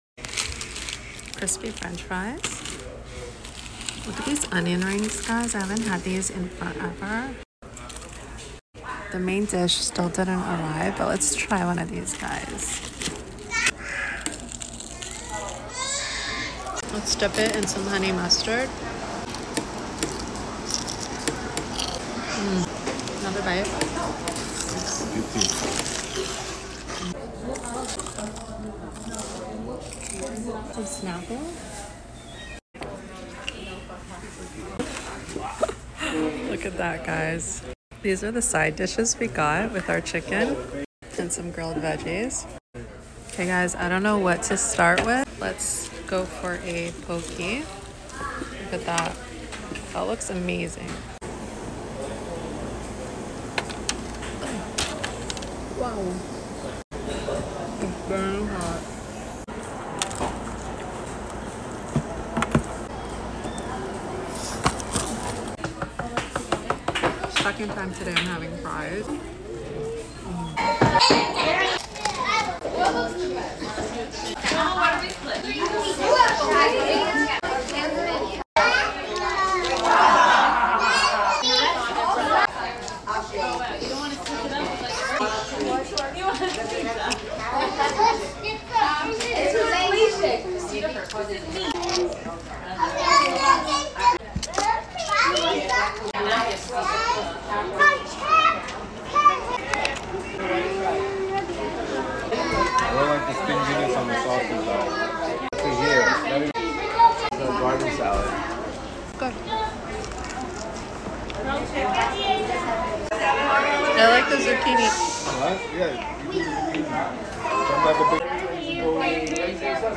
Public Eating Fried Chicken Onion sound effects free download